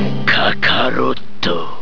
In questa pagina potete trovare i suoni in formato WAV / MP3 dei vari attacchi e delle tecniche speciali dei personaggi.